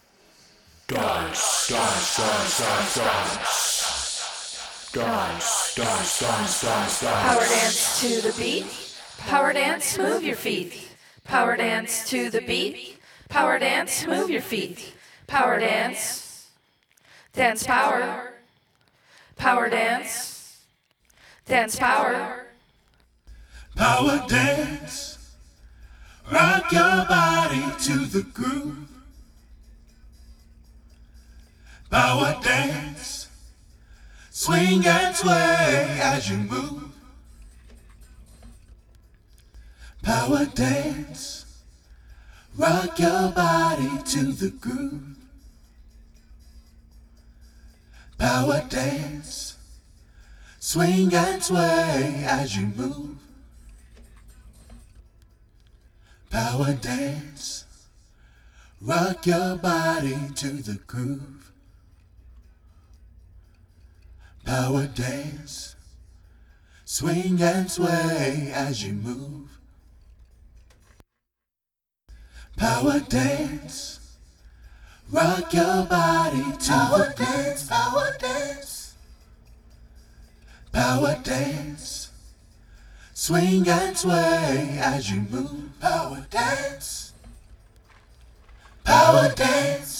ジャンル(スタイル) NU DISCO / DEEP HOUSE